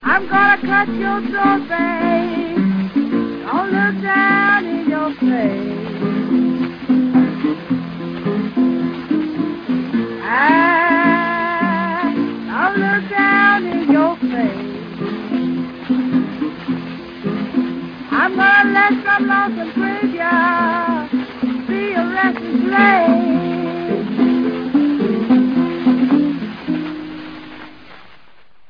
Несмотря на истинно женскую чувственность ее голоса